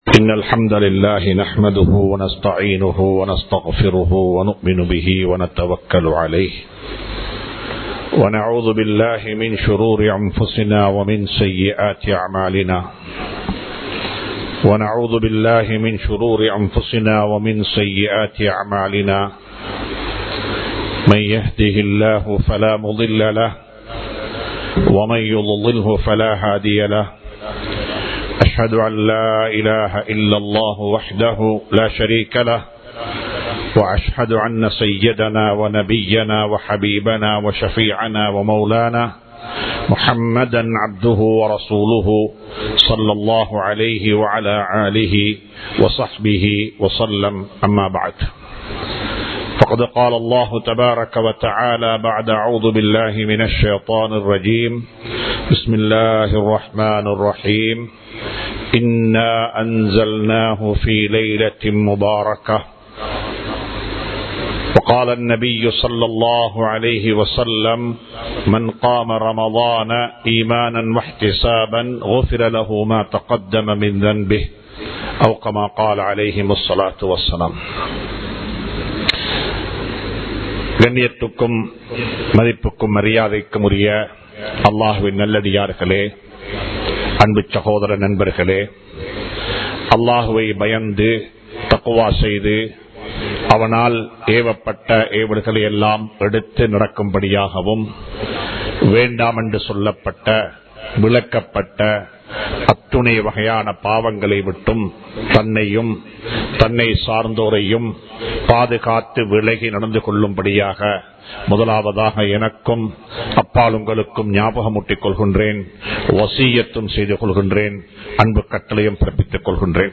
கண்டுகொள்ளாத பாவங்கள் | Audio Bayans | All Ceylon Muslim Youth Community | Addalaichenai
Muhiyadeen Jumua Masjith